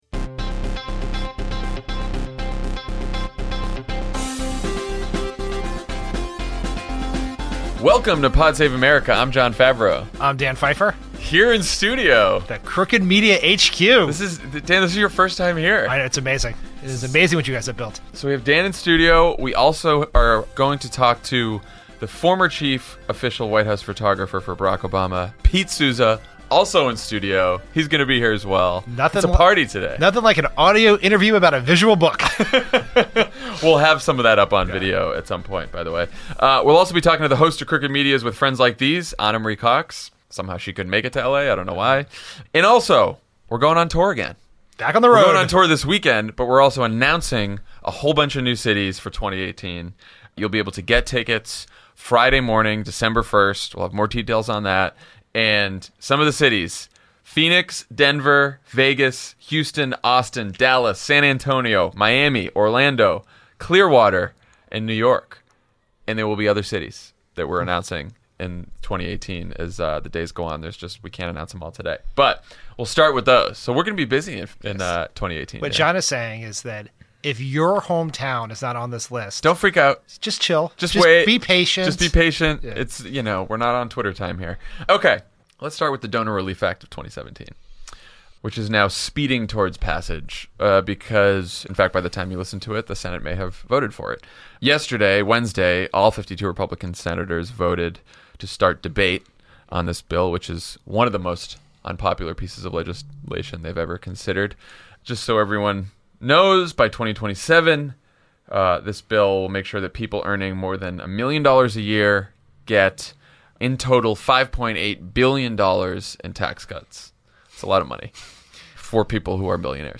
Republicans do whatever it takes to pass their Donor Relief Act, and Trump reminds us why he’s unstable and unfit to hold office. Then White House photographer Pete Souza joins Jon and Dan in studio to talk about his bestselling new book, Obama: An Intimate Portrait, and Ana Marie Cox calls in to discuss the latest sexual assault revelations.